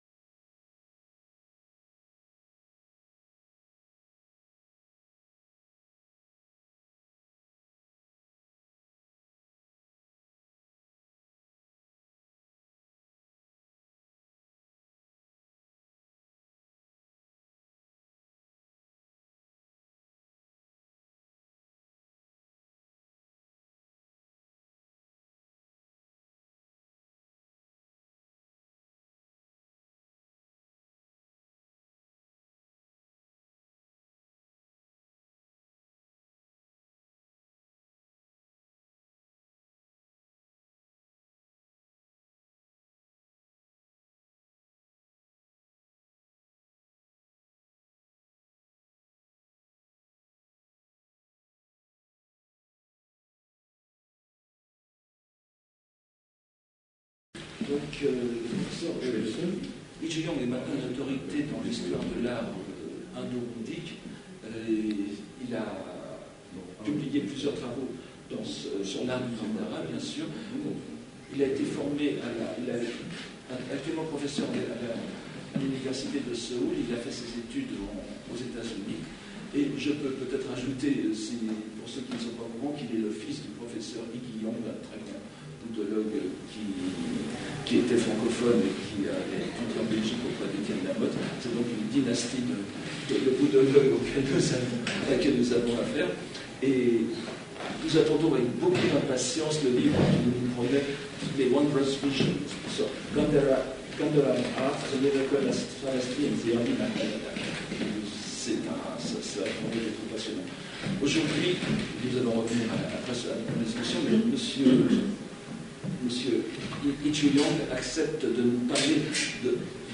This lecture will explore this remarkable monument in Korean Buddhism focusing on its relationship to Indian prototypes.